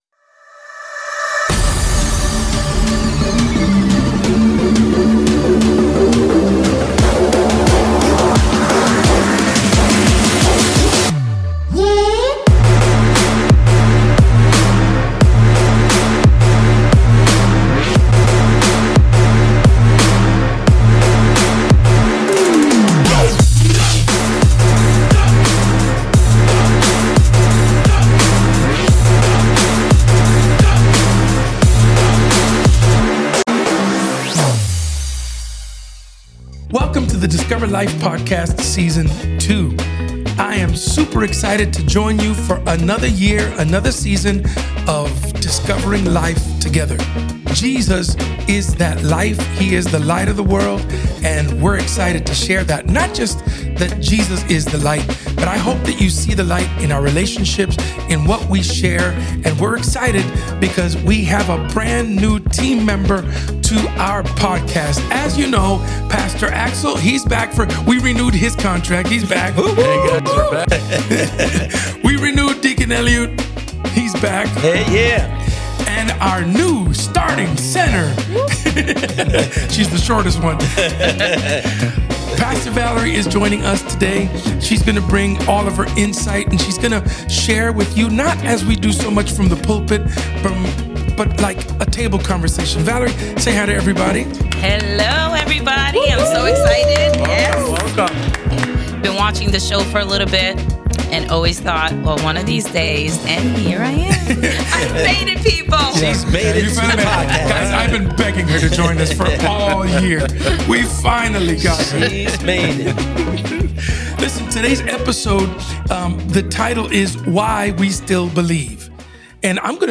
Why We Still Believe launches Season 2 of the Discover Life Podcast with an honest and timely conversation about faith in a weary world.